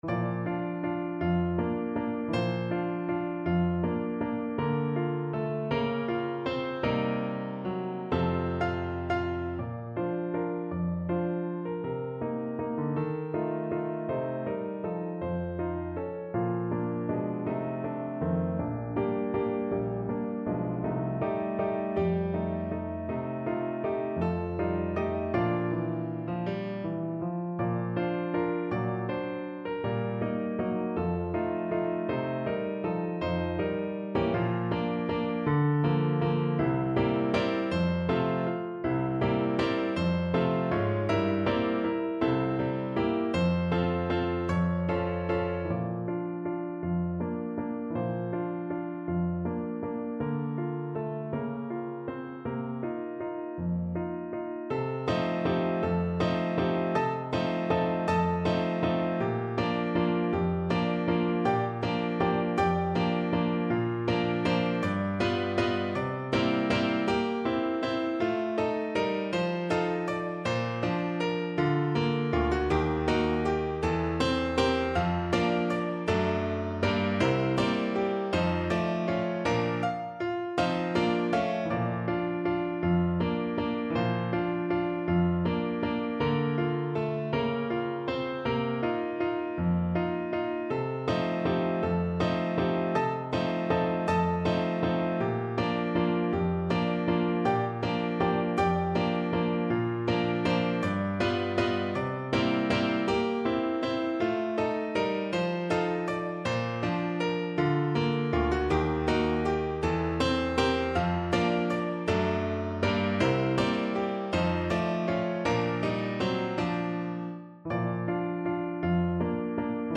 3/4 (View more 3/4 Music)
~ = 160 Valse moderato
Classical (View more Classical Voice Music)